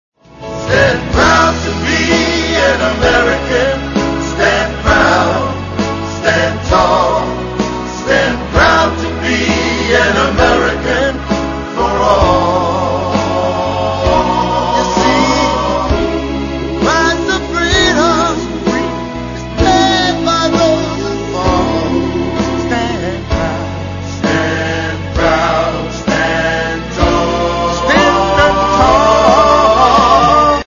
vocal group